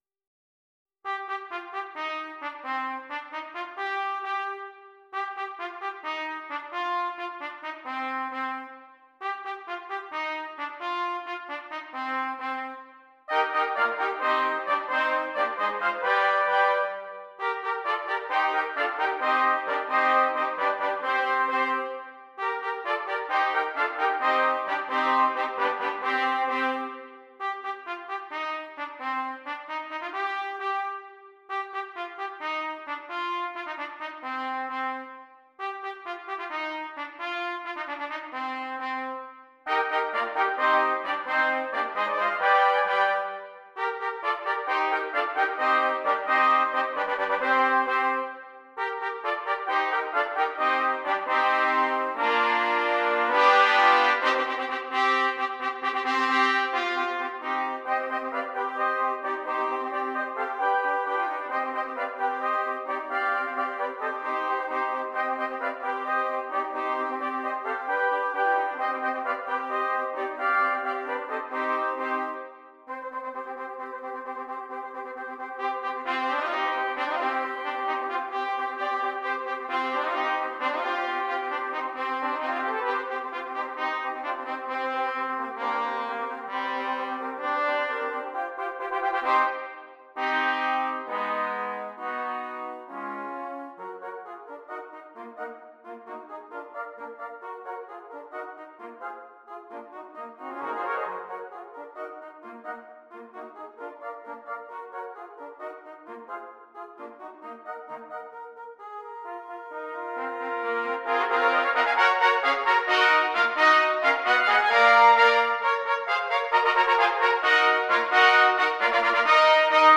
6 Trumpets
Traditional Spanish Carol